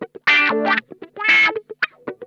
Index of /90_sSampleCDs/Zero G - Funk Guitar/Partition B/VOLUME 021
THROATWAH 4R.wav